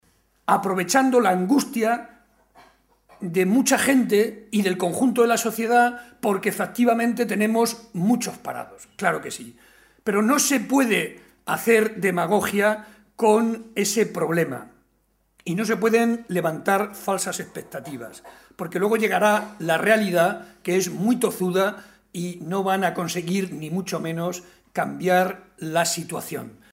Barreda argumentó que la realidad es más complicada que las “falsas expectativas” que lanzan los responsables políticos del PP, y para comprobarlo, señaló que tan sólo hay que ver los casos de Portugal, Castilla-La Mancha o Villarta de San Juan, localidad en la que intervino en un acto público, donde se han producido esos relevos de Gobierno y “las cosas, lejos de mejorar, han ido a peo”, demostrando que la realidad es mucho más tozuda y no van a conseguir, ni mucho menos, mejorar la situación”.